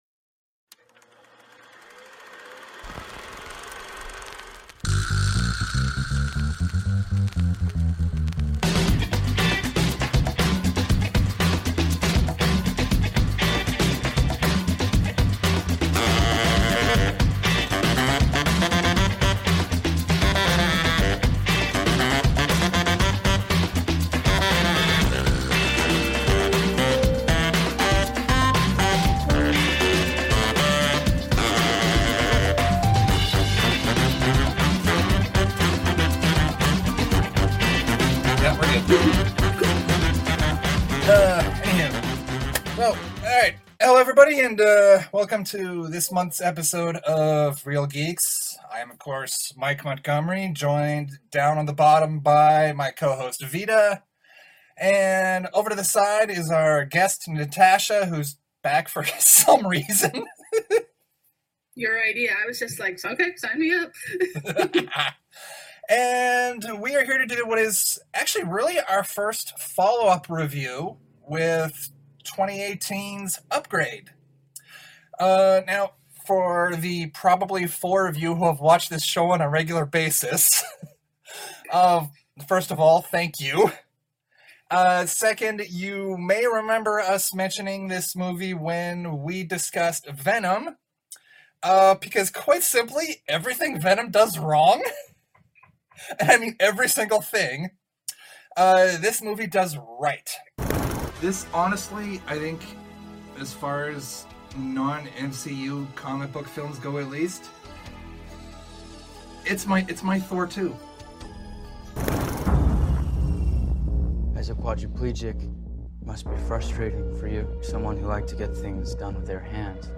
Originally recorded in Halifax, NS, Canada
Video: Whereby Video Conferencing